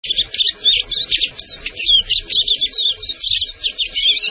En cliquant ici vous entendrez le chant du Tarin des aulnes
Le Tarin des aulnes